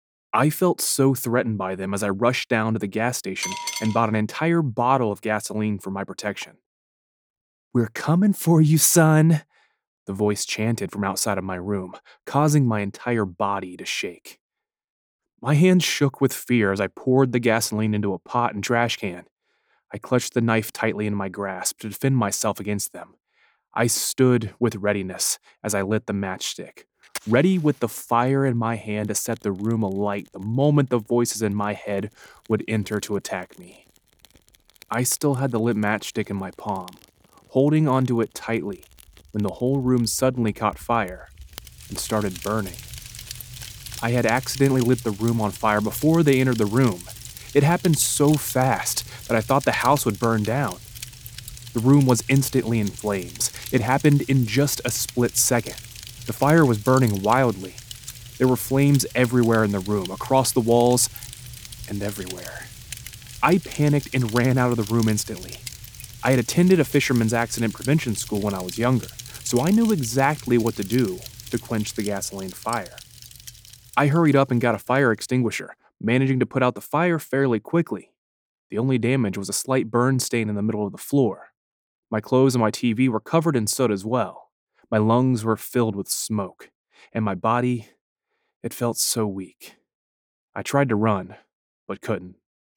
Through The Wall as audio book
Audio sample for the audio book - Through the Wall: A Targeted Individual.